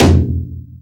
neiro_1_don.wav